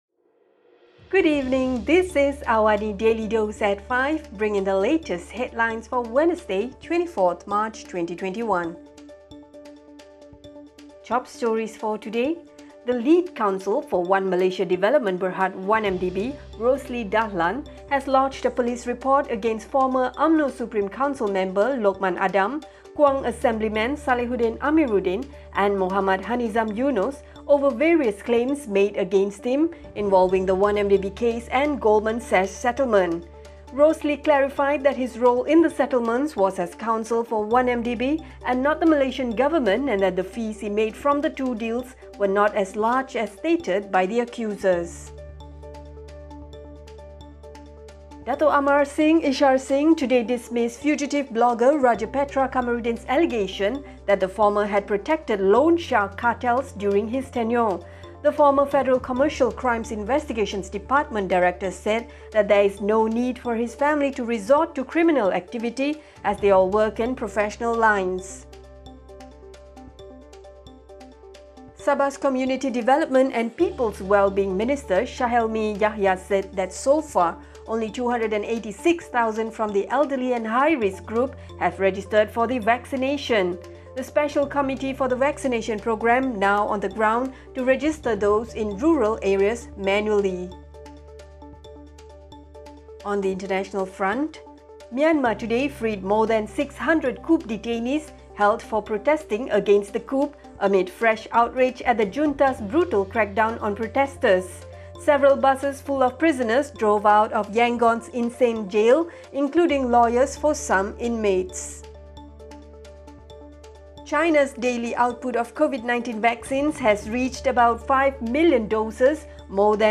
Also, hundreds of people imprisoned for protesting Myanmar’s Feb 1 coup, were released today in the first apparent gesture by the military to try to placate the protest movement. Listen to the top stories of the day, reporting from Astro AWANI newsroom — all in 3-minutes.